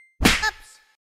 Subway Surfers crash-into-train sound effect.
subway-surfers-crash.mp3